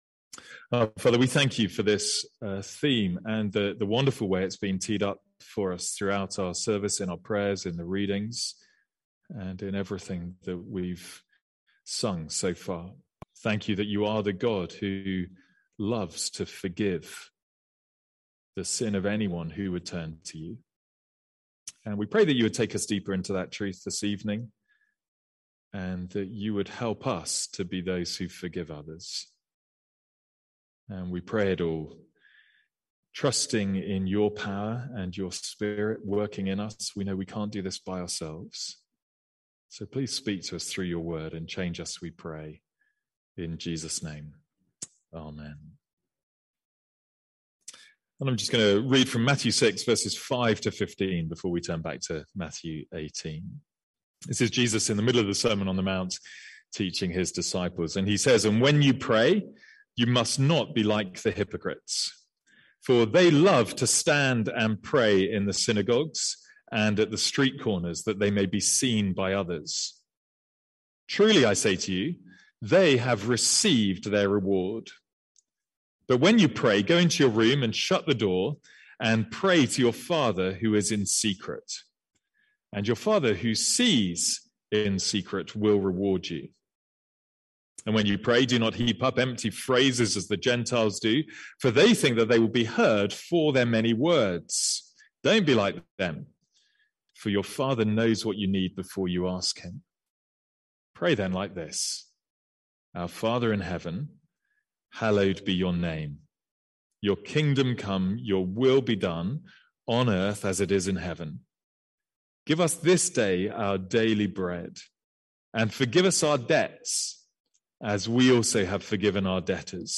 Sermons | St Andrews Free Church
From our evening series in the Lord's Prayer.